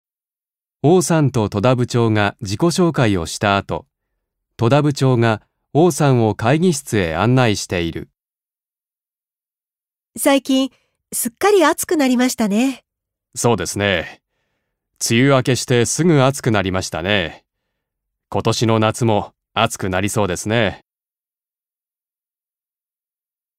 1.2. 会話 （雑談ざつだんをする）